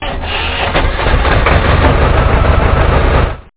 TractorStart.mp3